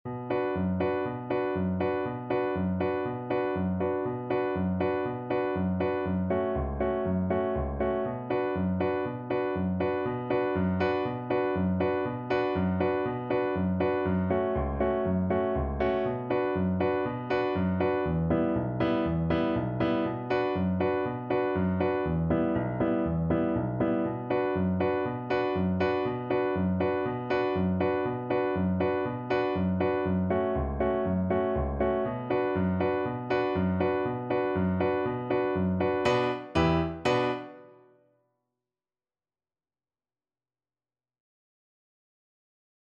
Allegro vivo (View more music marked Allegro)
4/4 (View more 4/4 Music)